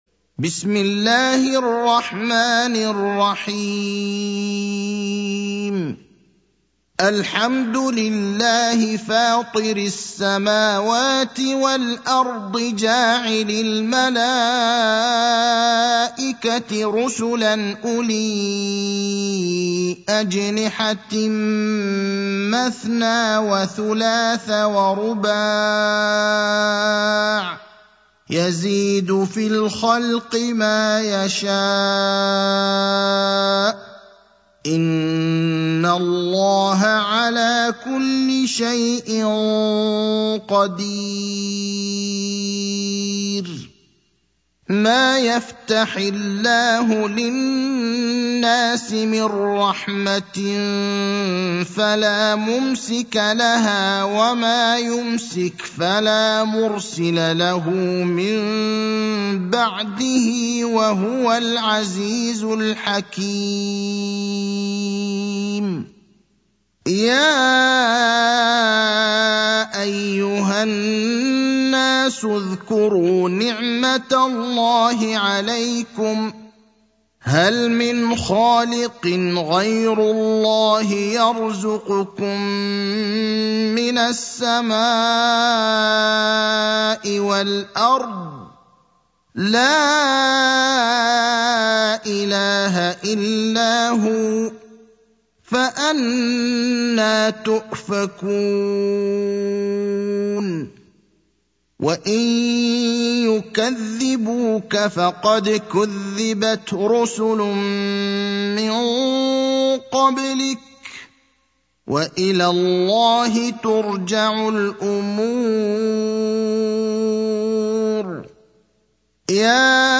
المكان: المسجد النبوي الشيخ: فضيلة الشيخ إبراهيم الأخضر فضيلة الشيخ إبراهيم الأخضر فاطر (35)